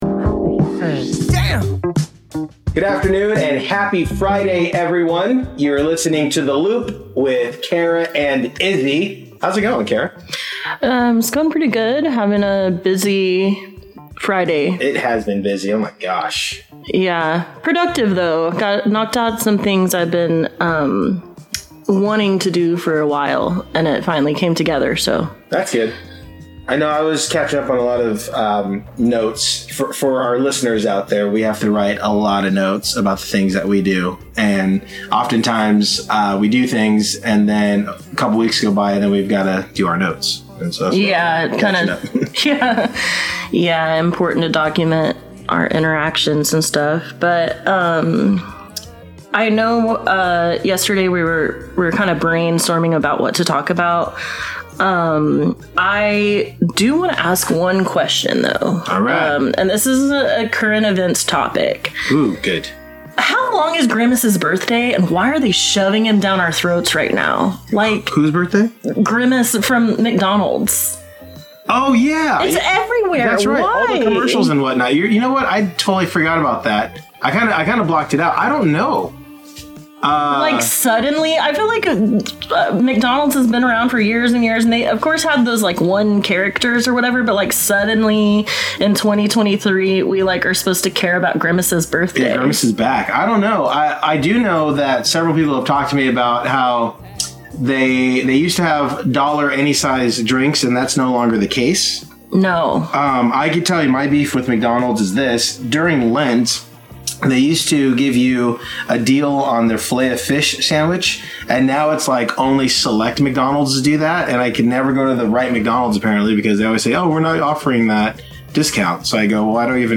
This episode aired live on CityHeART Radio on Friday July 7, 2023 at lunch time. This week on The Loop, they talked fast food chains, the long lost dollar menu, birthdays, half-birthdays and more.